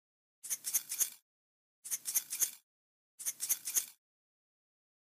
Tiếng Rắc muối, gia vị từ hủ, chai, lọ…
Thể loại: Tiếng ăn uống
Description: Tiếng rắc muối, tiếng rắc gia vị, tiếng rưới muối, tiếng rắc đường, tiếng đổ gia vị, tiếng rắc tiêu, tiếng rắc bột, Salt Shaker Sound Effect, gia vị từ hũ/chai/lọ – âm thanh “lách tách” hoặc “xào xạc” khi muối, đường, tiêu hay các loại gia vị được rắc hoặc đổ ra từ hũ, chai, lọ. Âm thanh tinh tế, rõ ràng, gợi cảm giác gần gũi trong gian bếp, thường xuất hiện trong các cảnh nấu ăn, chế biến món ăn.
tieng-rac-muoi-gia-vi-tu-hu-chai-lo-www_tiengdong_com.mp3